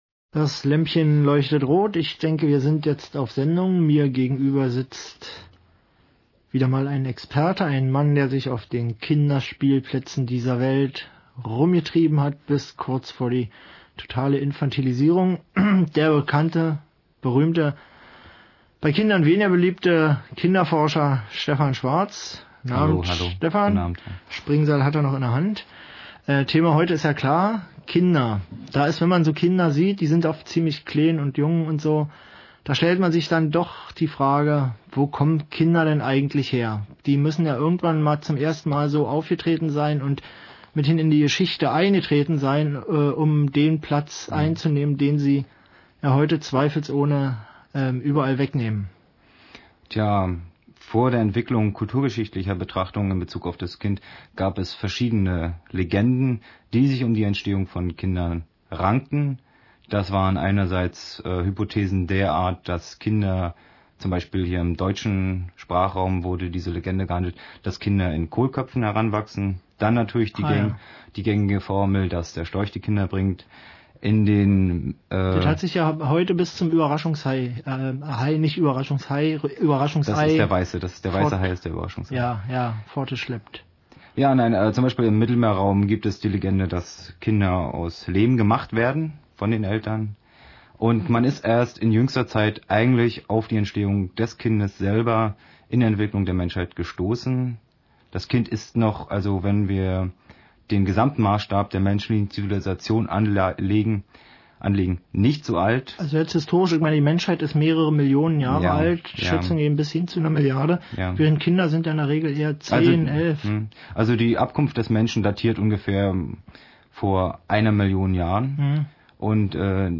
expertengespräche